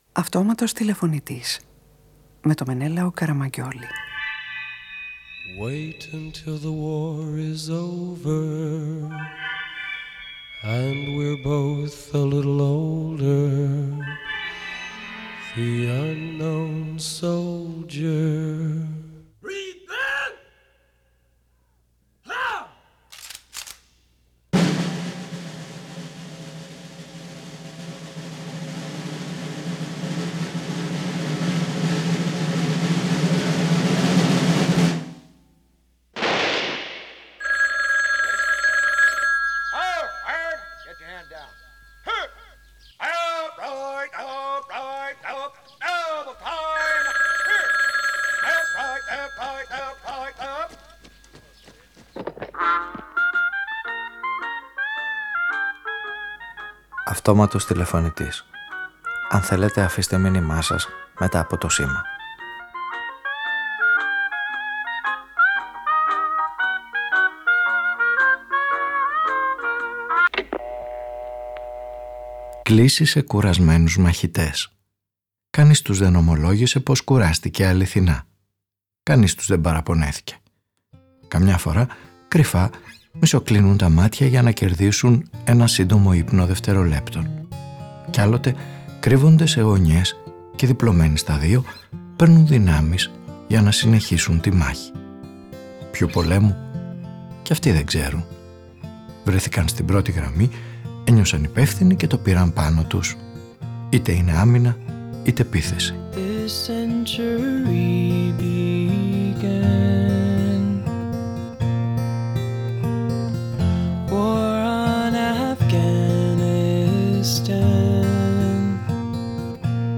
Μια ραδιοφωνική ταινία που γίνεται προειδοποίηση στον πόλεμο που εξυφαίνεται υπόγεια, καθημερινά κι υπονομευτικά.
Παραγωγή-Παρουσίαση: Μενέλαος Καραμαγγιώλης